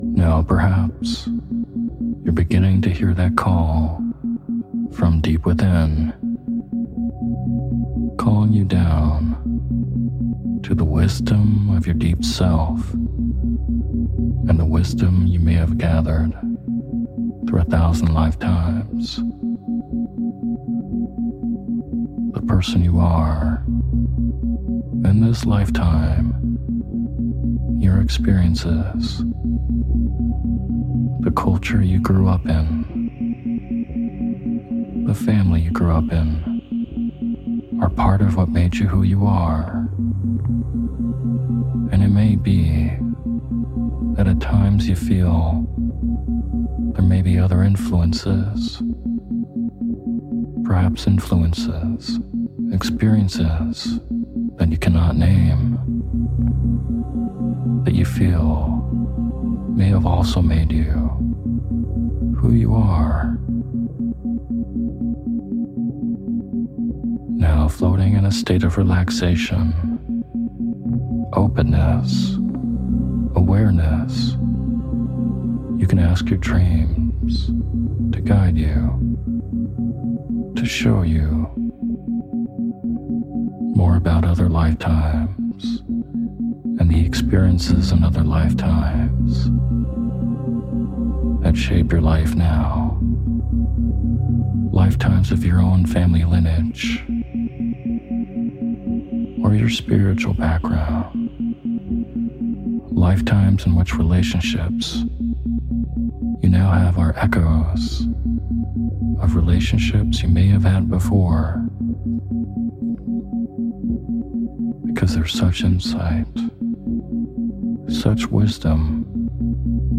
Sleep Hypnosis For Past Life Regression With Isochronic Tones